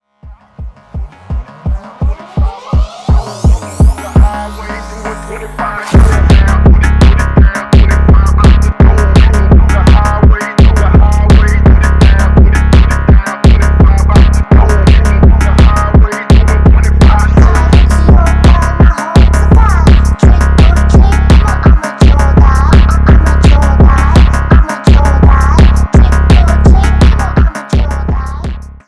Жанр: Электроника
# бас
# рэп, # фонк